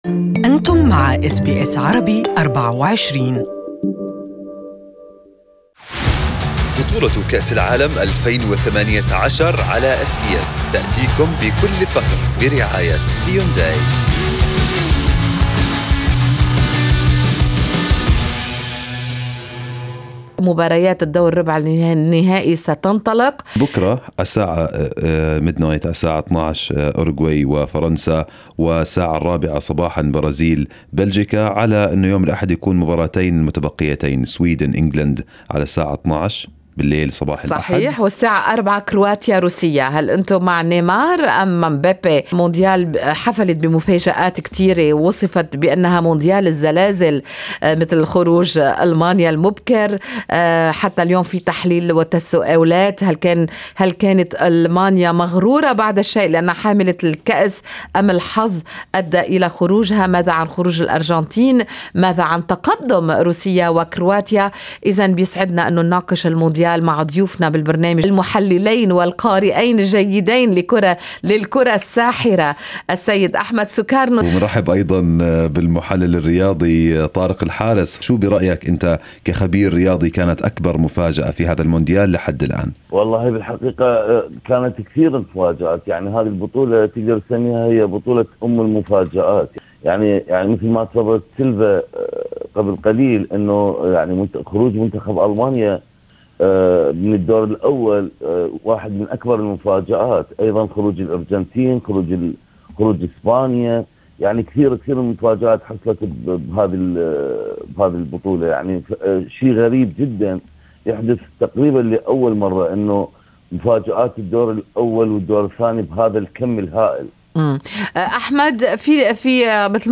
Good Morning Australia interviewed sport analysts